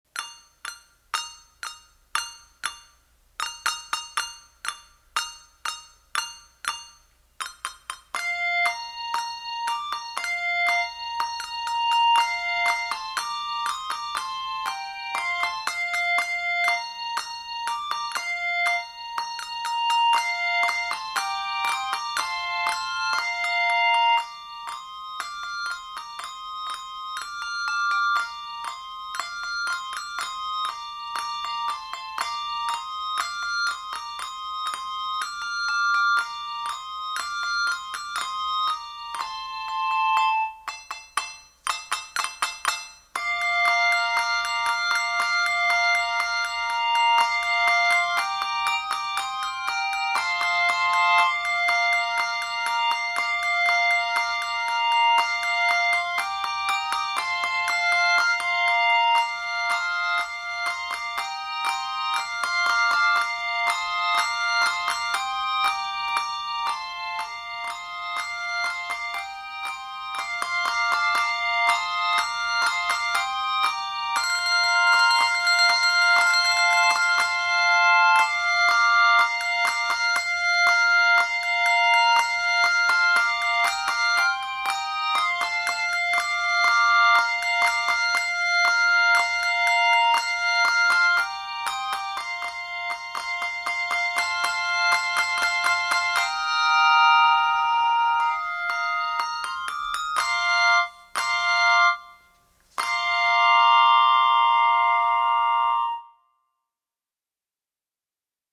upbeat arrangement